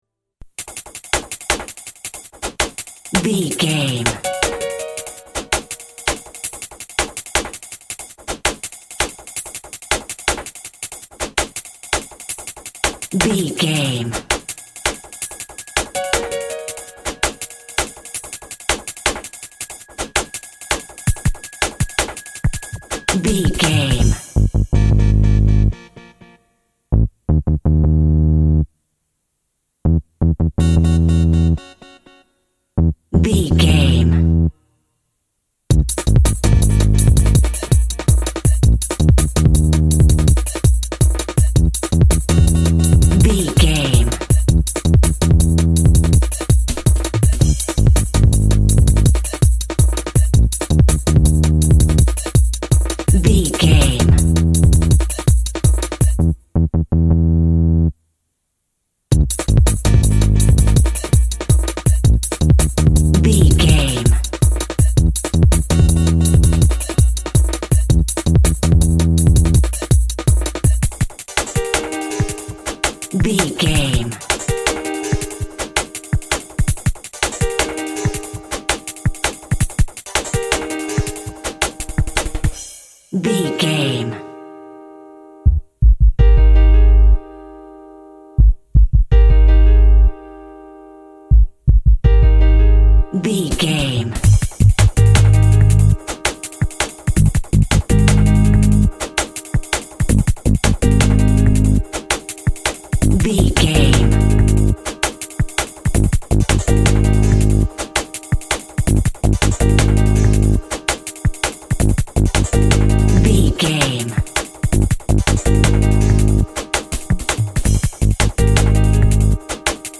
Aeolian/Minor
G#
Fast
driving
energetic
uplifting
hypnotic
industrial
drums
synthesiser
piano
nu jazz
downtempo
synth lead
synth bass